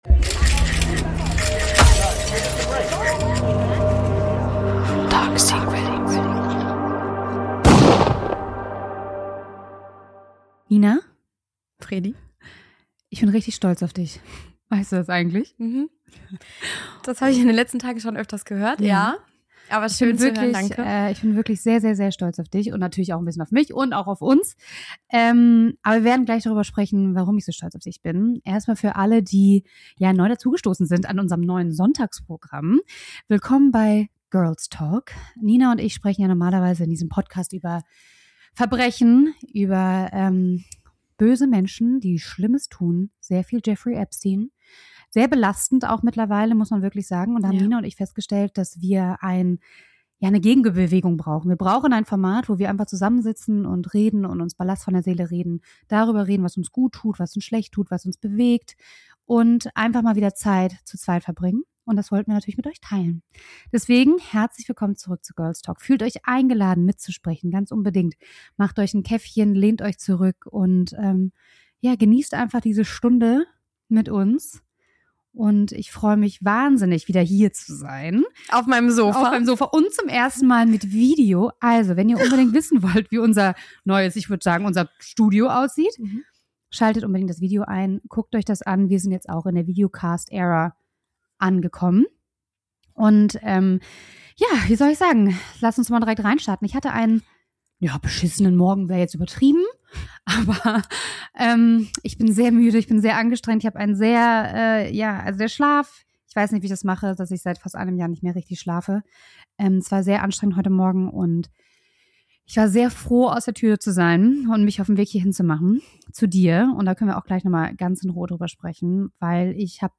Ein Girls-Talk über Druck, Erwartungen – und die Erlaubnis, nicht perfekt sein zu müssen.